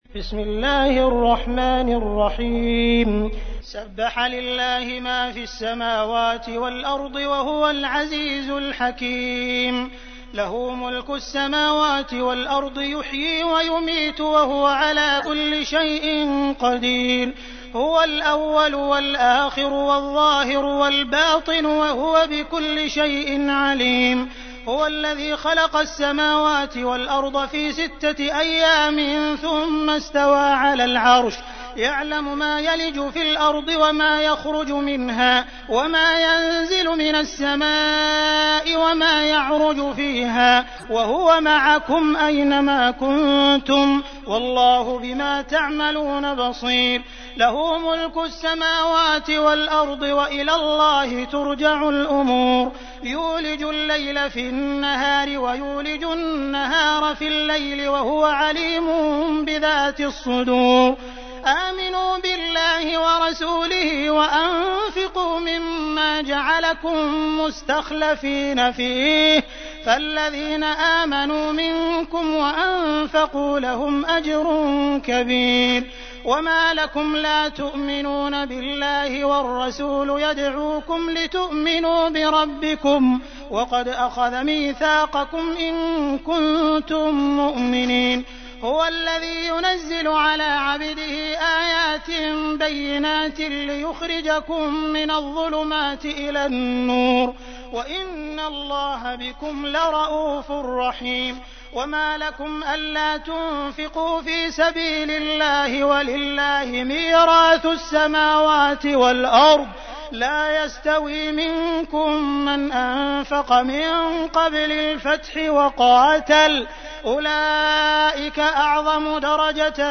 تحميل : 57. سورة الحديد / القارئ عبد الرحمن السديس / القرآن الكريم / موقع يا حسين